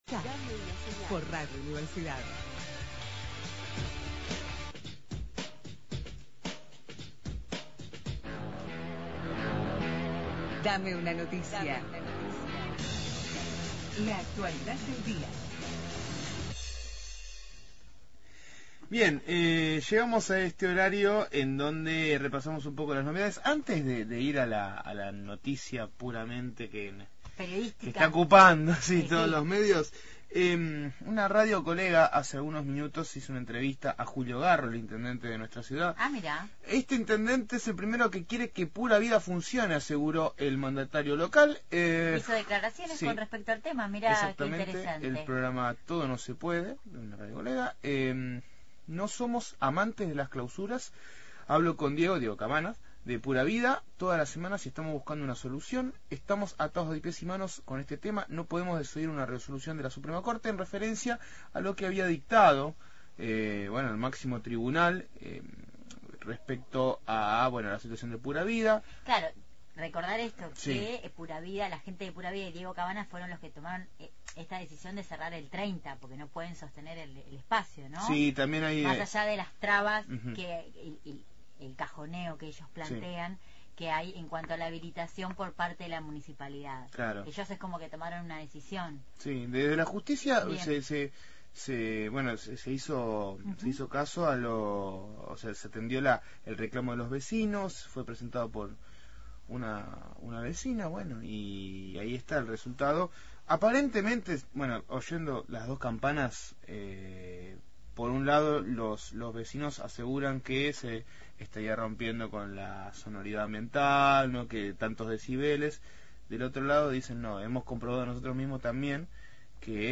Síntesis informativa de la primera tarde – Radio Universidad